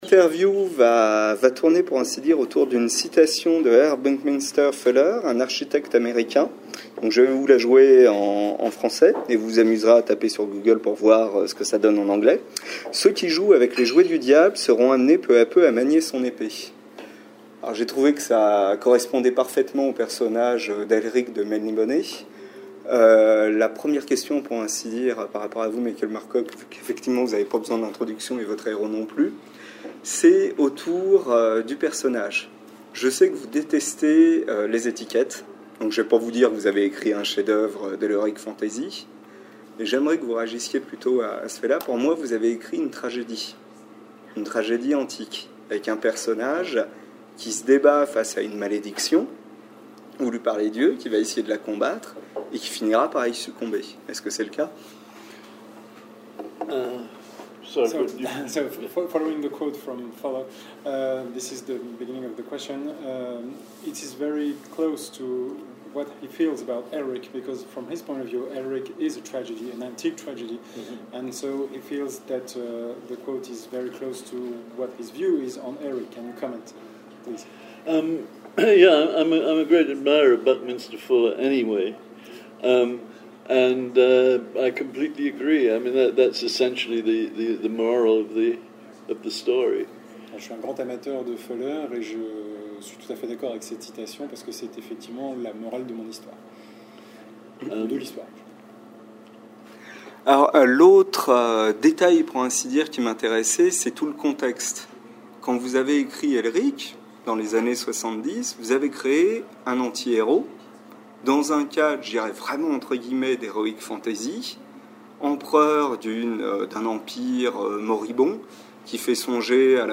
Interview autour d'Elric